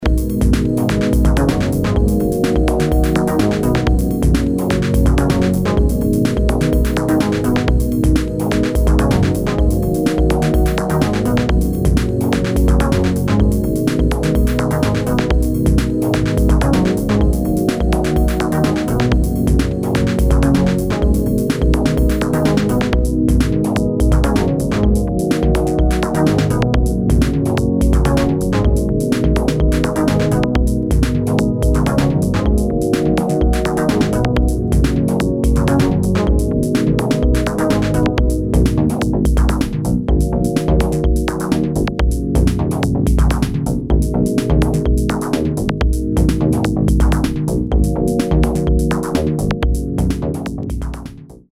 [ TECHNO / MINIMAL ]